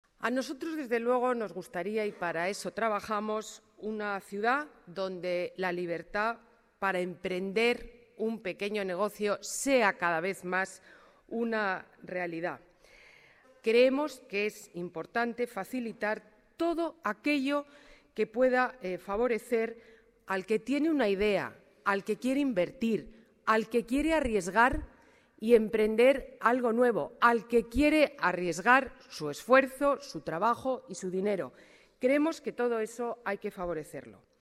Nueva ventana:Ana Botella explica en qué consiste la Tax Free